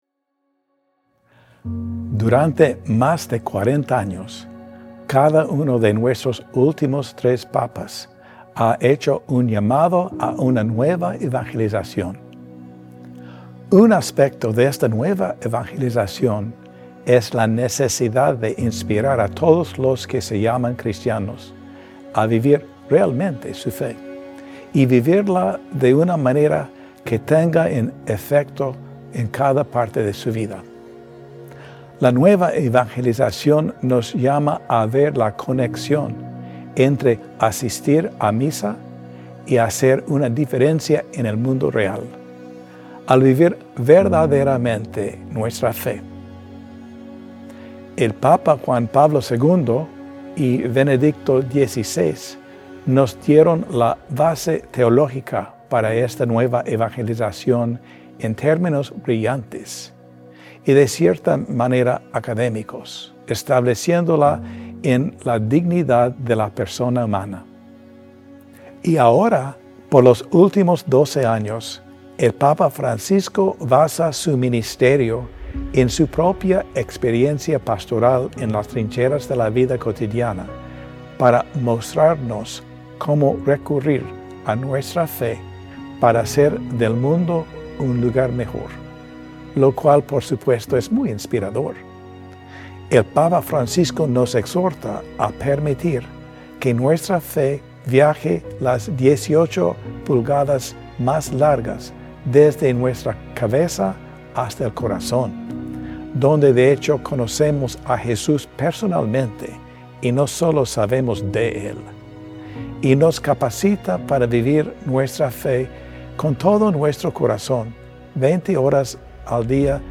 El Obispo Anthony B. Taylor grabó la siguiente homilía sobre la Campaña Católica de Arkansas a Compartir, la cual se escuchó en todas las Misas en Arkansas, el 1 y 2 de febrero, 2025.